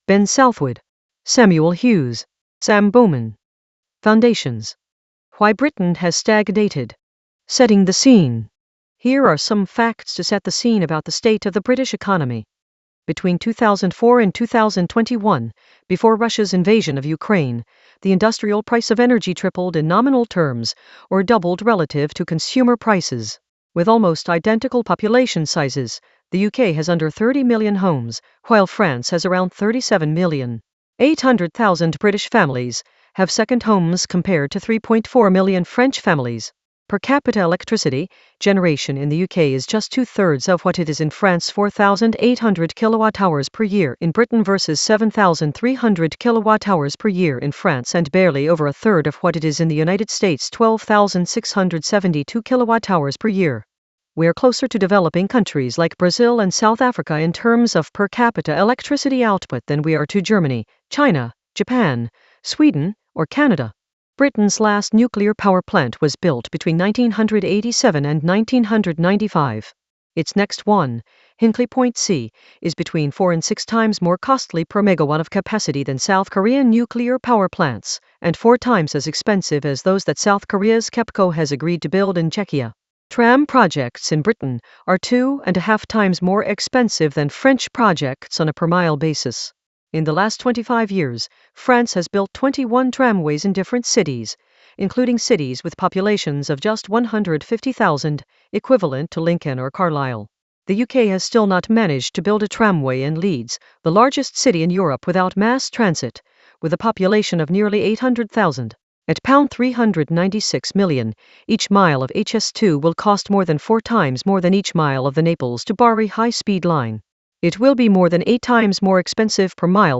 Foundations. Why Britain has stagnated - audiobook